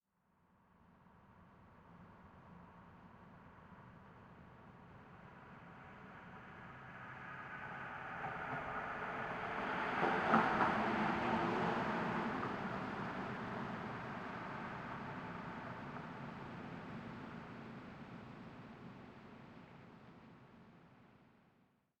1Shot Vehicle Passby with Tire Bumps ST450 06_ambiX.wav